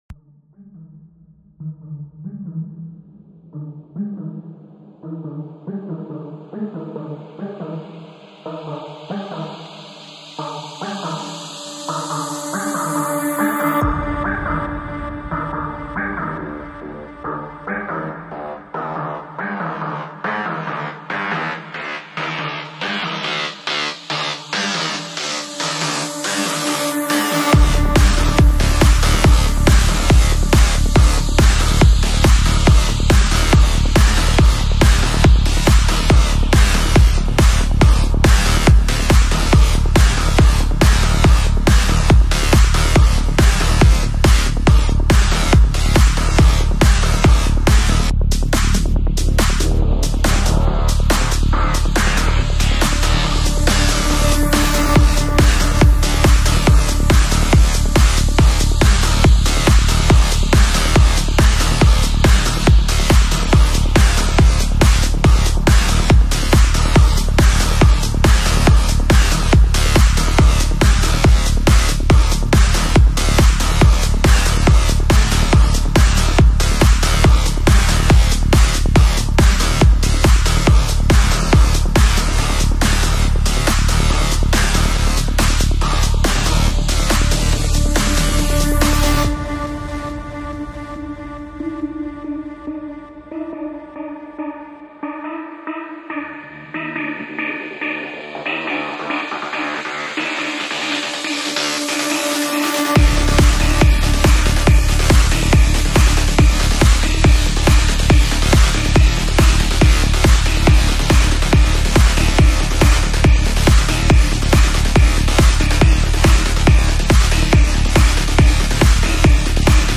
Industrial music : REPLY mp3 format.